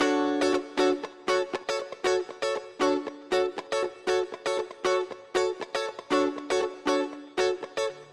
12 Guitar PT1.wav